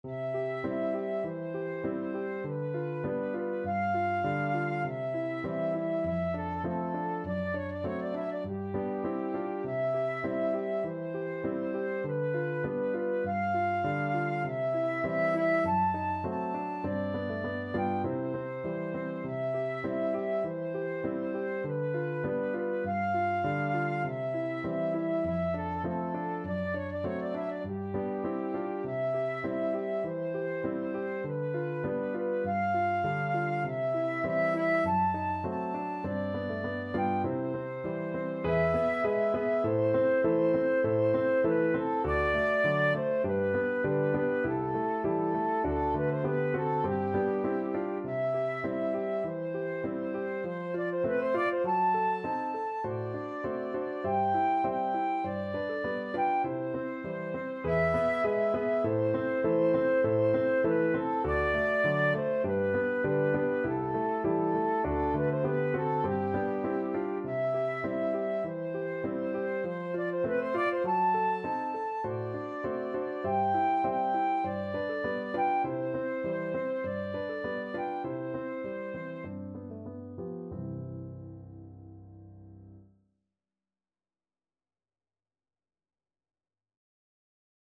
4/4 (View more 4/4 Music)
Flute  (View more Easy Flute Music)
Classical (View more Classical Flute Music)